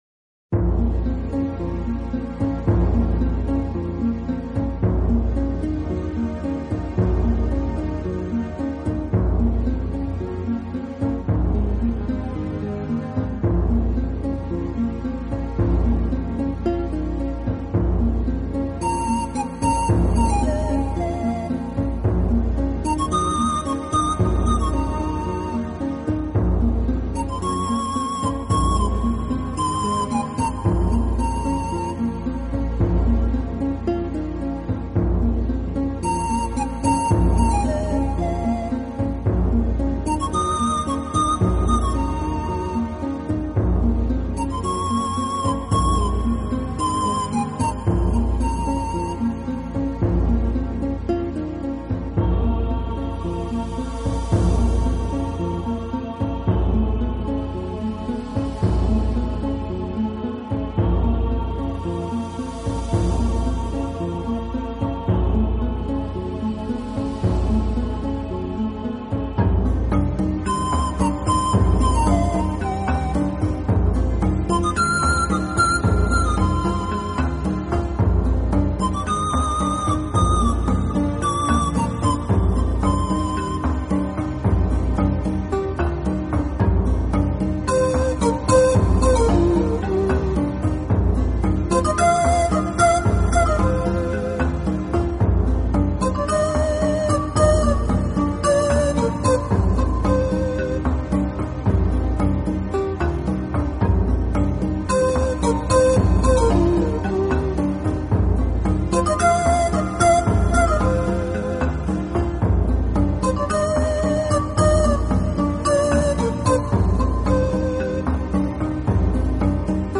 音乐类型：NewAge 新世纪